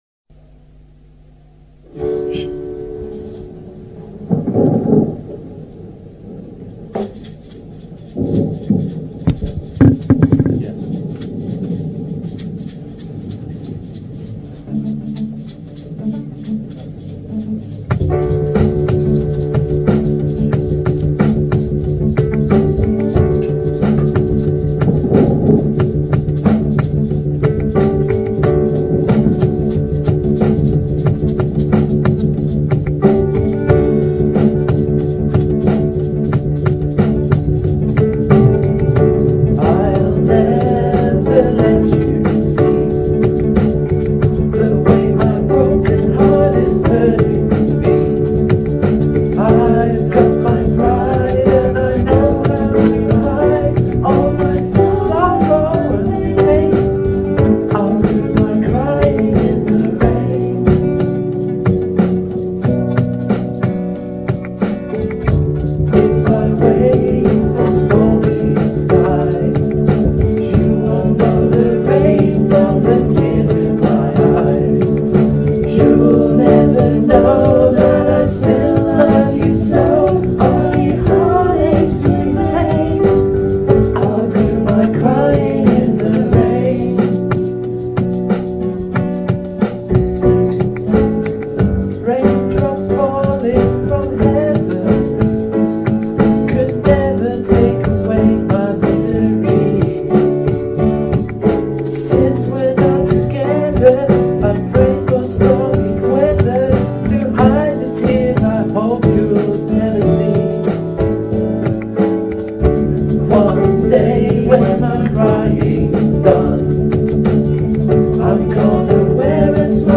Emotionally charged late-night session.